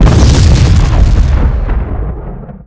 rocket_blackbox_explode1.wav